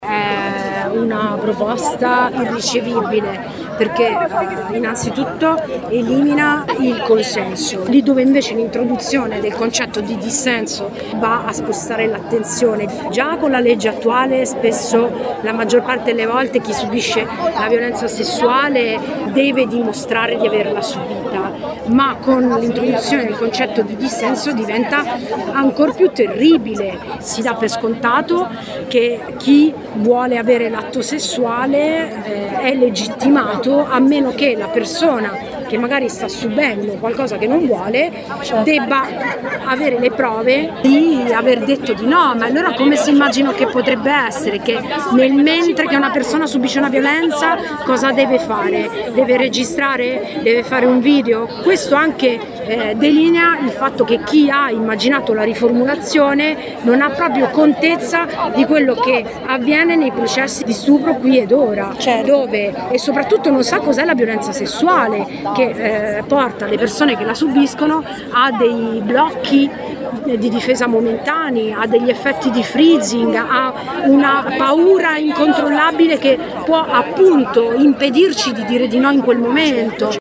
Al momento del voto, fuori dal Senato c’è stata la protesta delle associazioni e dei centri anti-violenza.